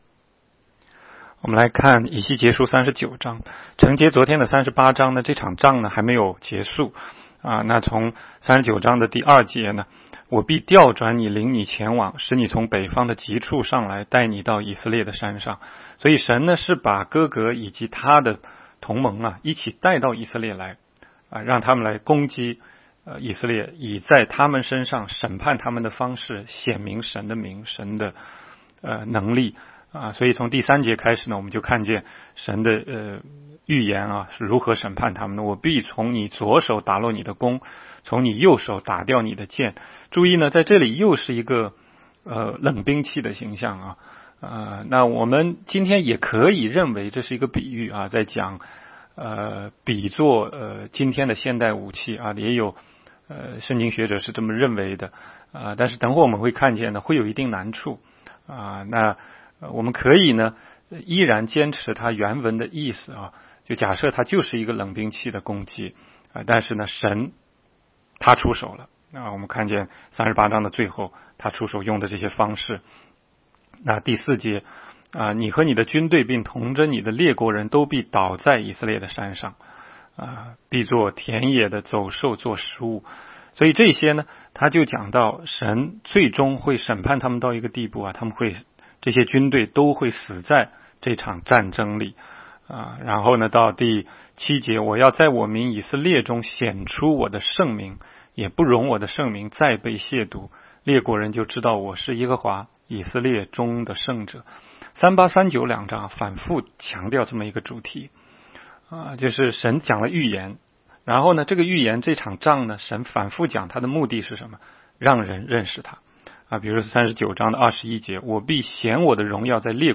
16街讲道录音 - 每日读经 -《以西结书》39章